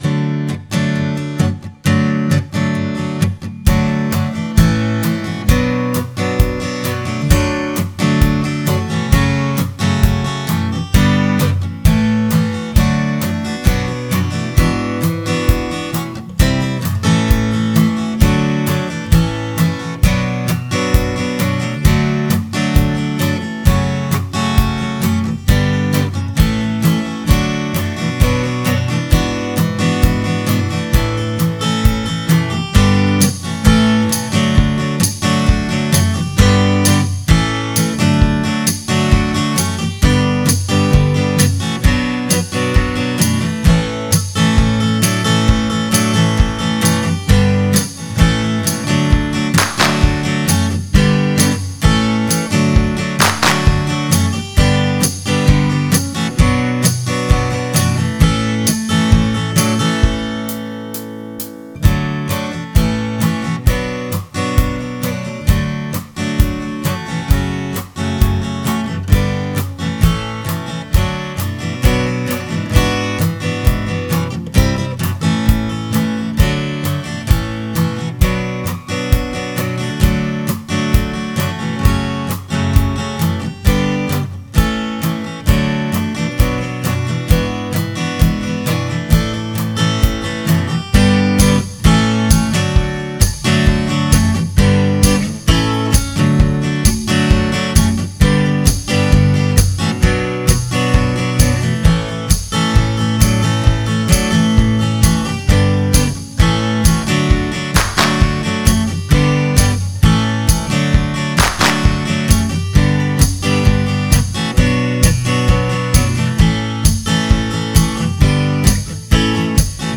Bande-son instrumentale Chant des Aventuriers
Le-chant-des-aventuriers-instrumental.wav